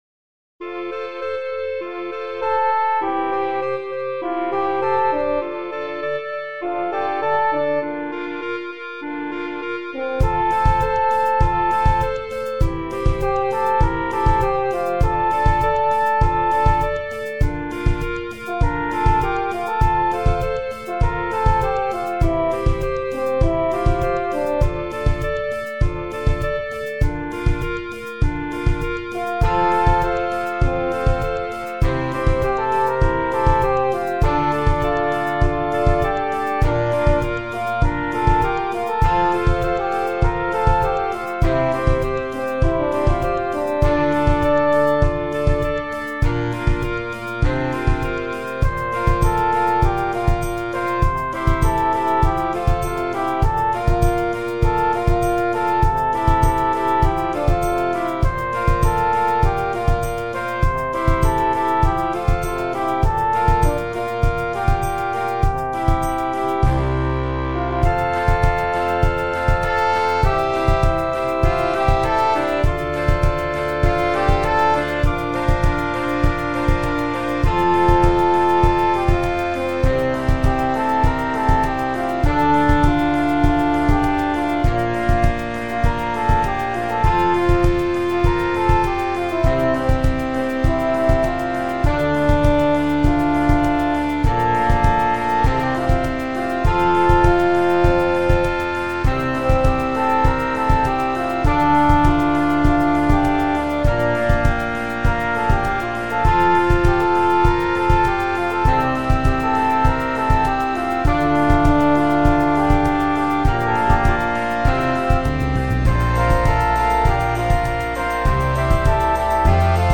Kategorie: Pop
frisch und beschwingt
Fl�gelhorn